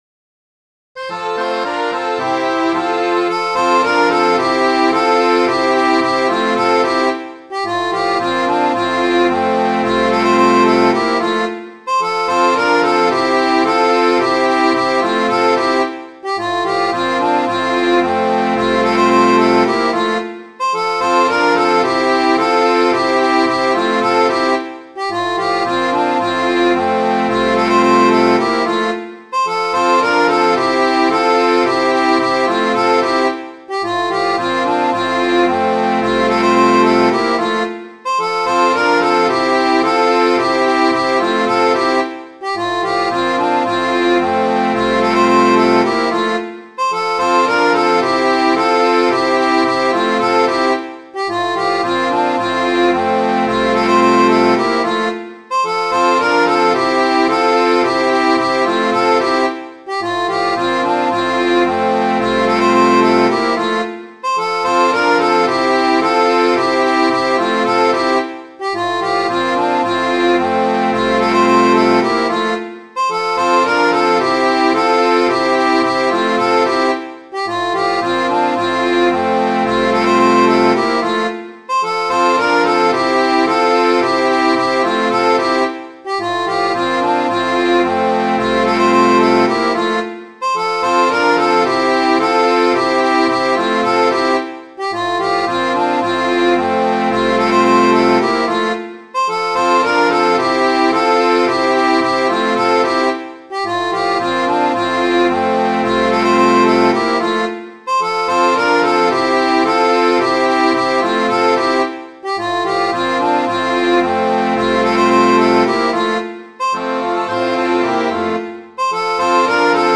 Midi File, Lyrics and Information to Reuben Ranzo
This song was a popular halyard shanty , particularly popular among whalers.